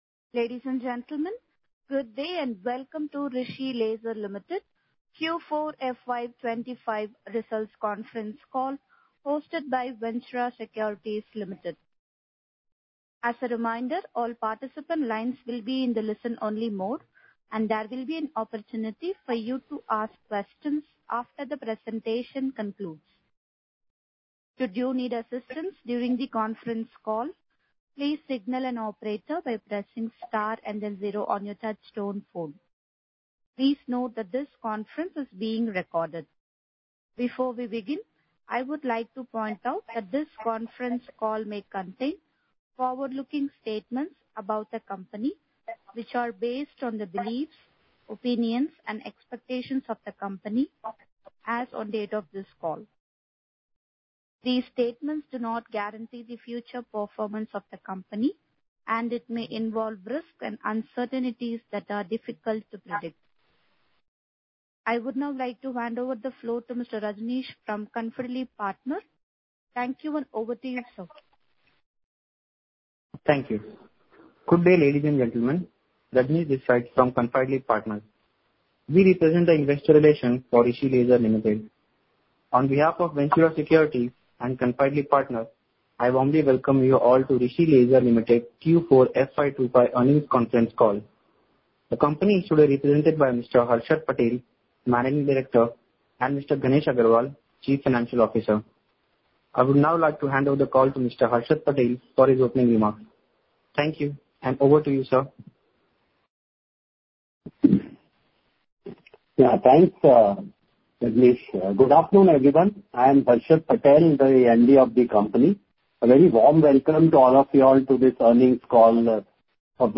Recording of Investor Meet